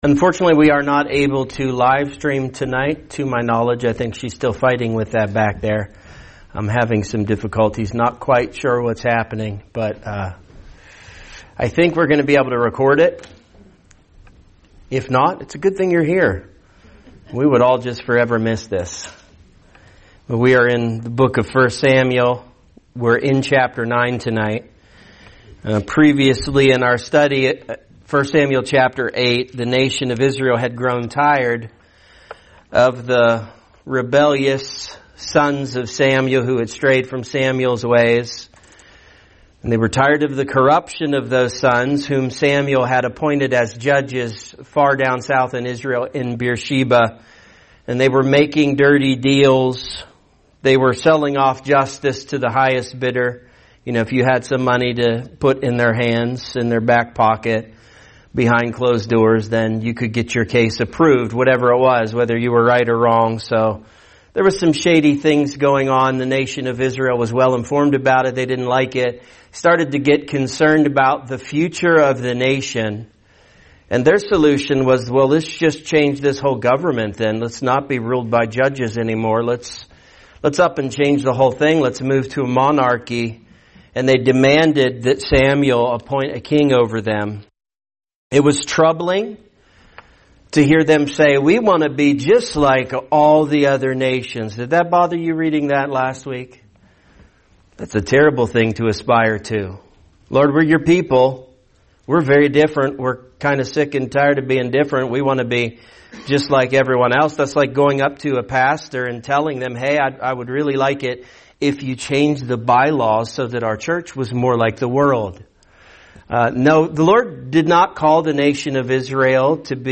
A message from the topics "The Book of 1 Samuel."